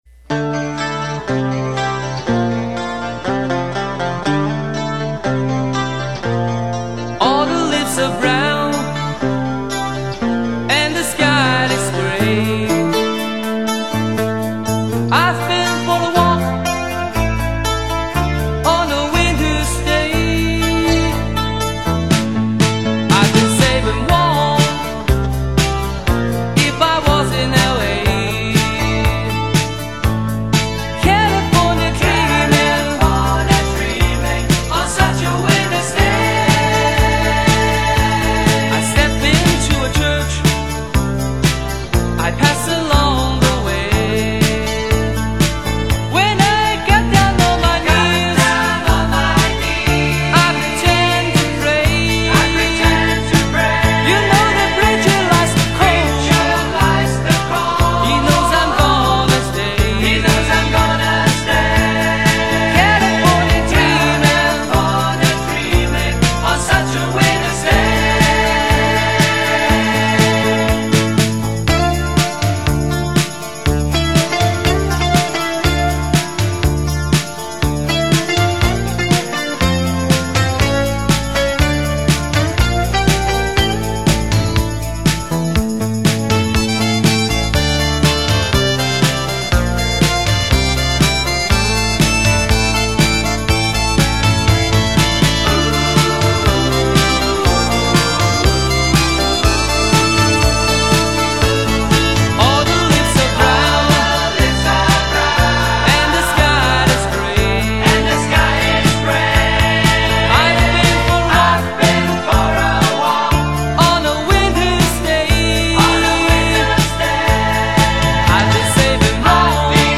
语    种：纯音乐
低吟浅唱，有如天籁，直触人心，荡埃涤尘。木吉他的朴素和弦，民谣歌手的真情演绎，帮我们寻回人类的童真，生活的安宁。